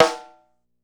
DrRim2.wav